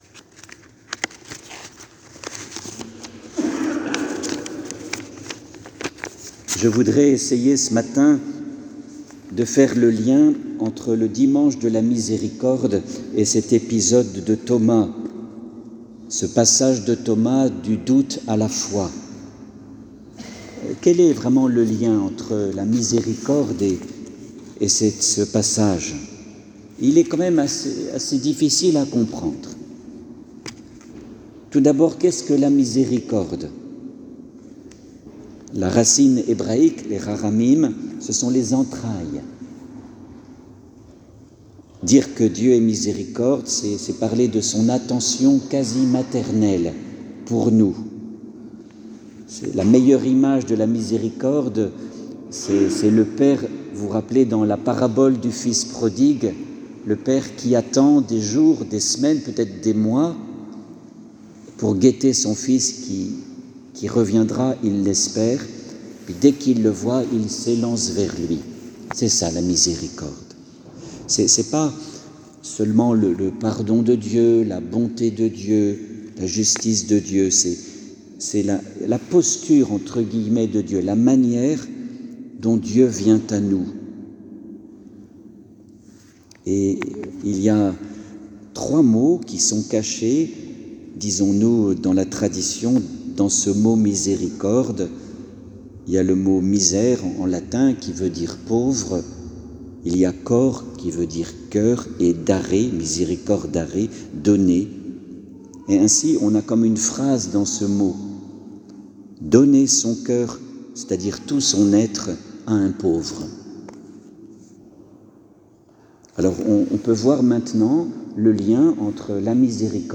Homelie-dimanche-de-la-misericorde.mp3